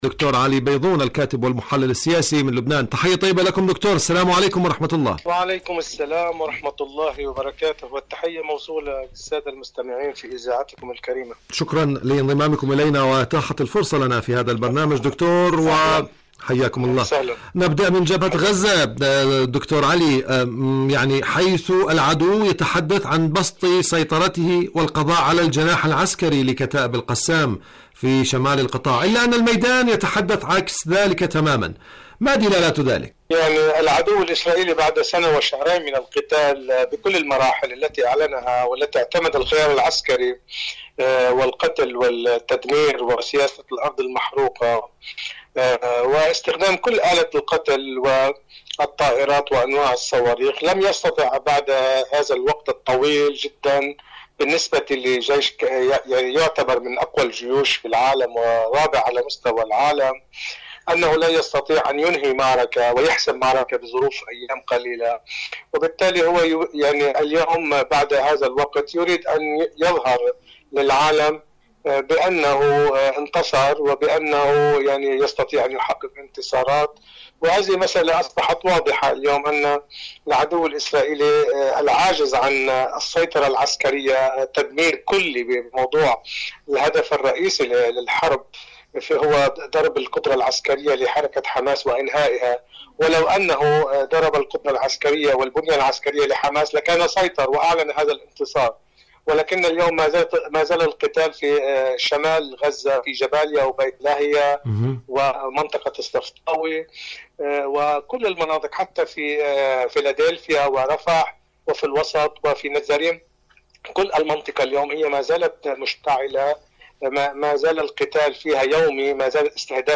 إذاعة طهران- فلسطين اليوم: مقابلة إذاعية